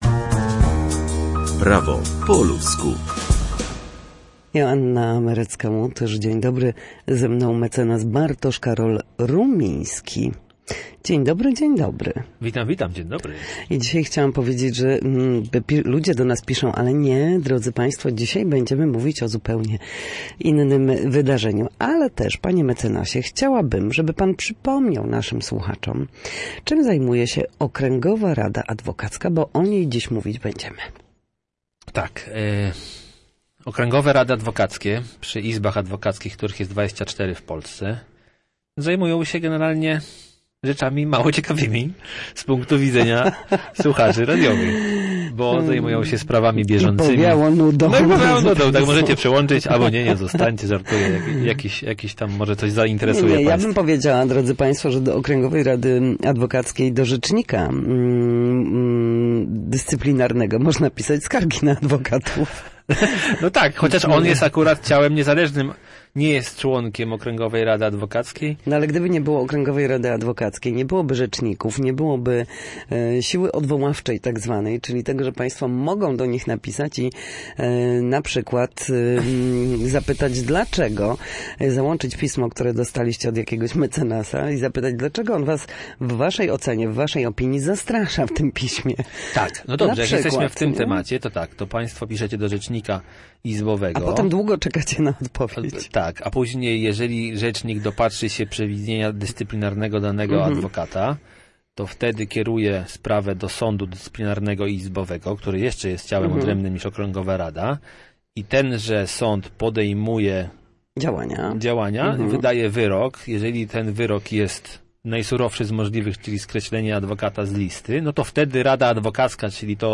W każdy wtorek o godzinie 13:40 na antenie Studia Słupsk przybliżamy meandry prawa. W naszym cyklu prawnym goszczą eksperci, którzy odpowiadają na jedno konkretne pytanie dotyczące zasad zachowania w sądzie lub podstawowych zagadnień prawnych.